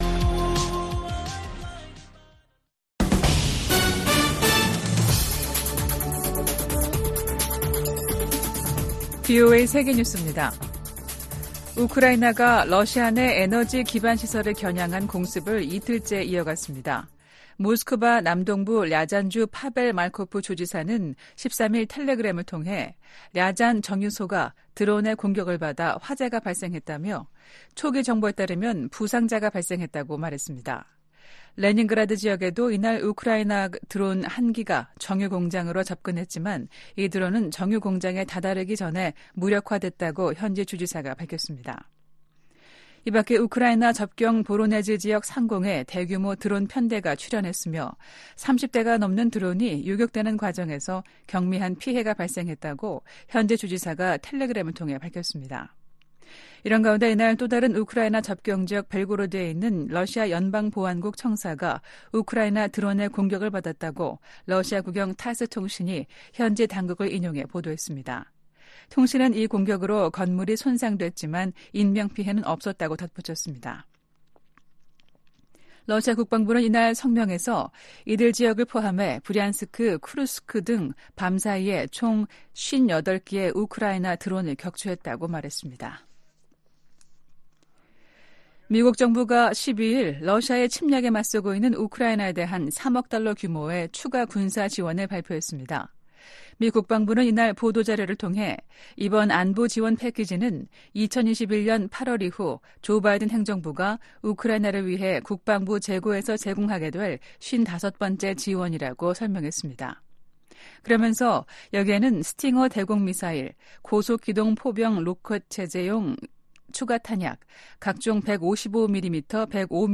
VOA 한국어 아침 뉴스 프로그램 '워싱턴 뉴스 광장' 2024년 3월 14일 방송입니다. 긴밀해지는 북한-러시아 관계가 김정은 국무위원장을 더 대담하게 만들 수 있다고 애브릴 헤인스 미 국가정보국장이 말했습니다.